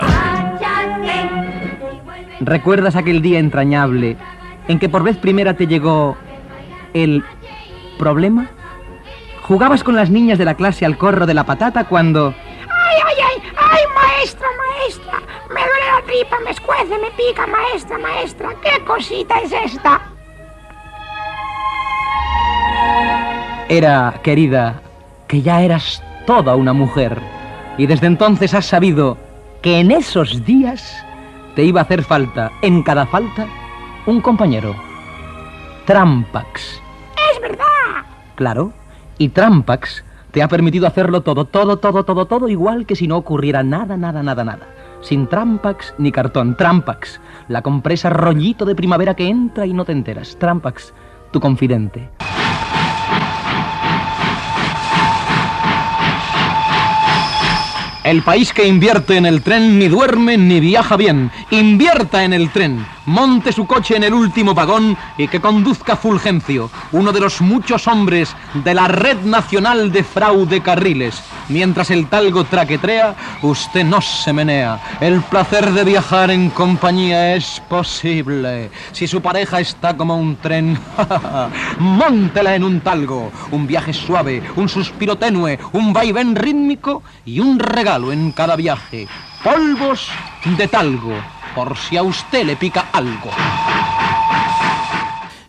Anuncis paròdics de la compresa "Trampax" i de la Red Fraudulenta de Ferrocarriles.
Entreteniment